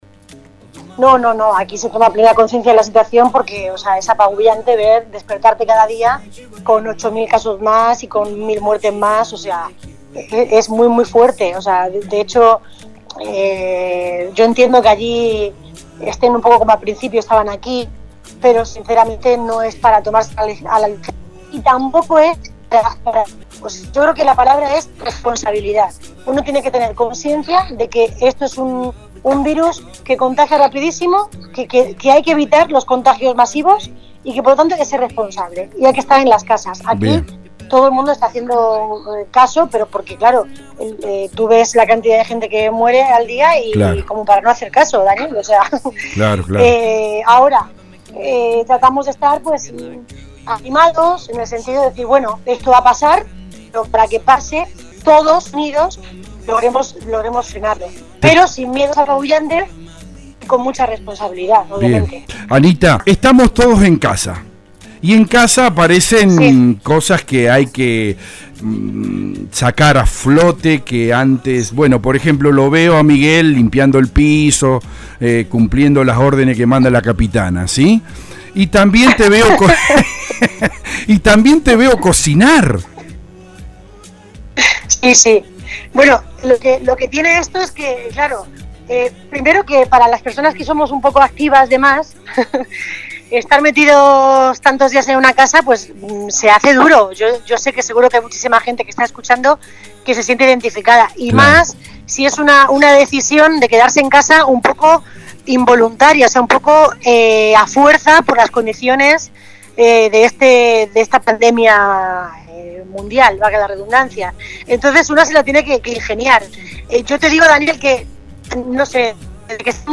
Así trajimos a nuestros micrófonos y al “aire de la radio”, desde el centro mismo de Madrid, para todos nuestros oyentes y lectores de Zárate y la región, a una madrileña de ley, artista que se ganó la Avenida Corrientes en la capital de Argentina y junto a Juan José Campanella su par, el argentino Luis Brandoni, descollaron con Parque Lezama en la capital española, la linda ciudad que hoy vive días tristes por el Coronavirus.